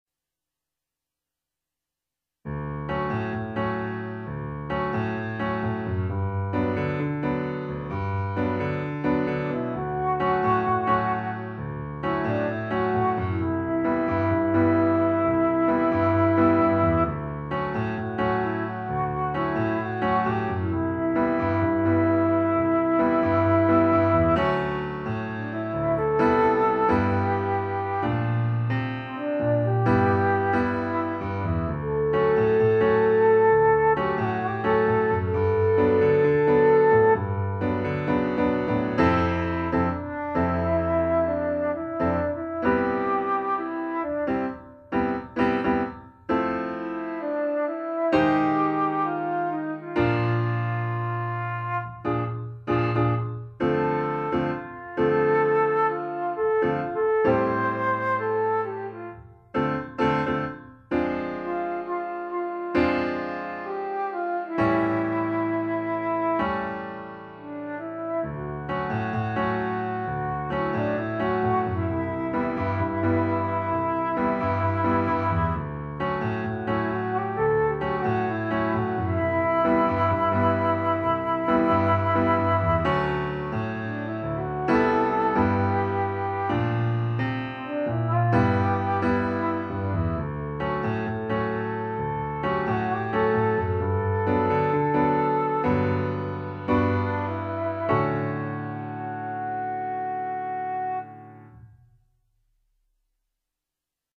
Instrumentation: C, Bb, Eb, pno,
instrument with pno accompaniment.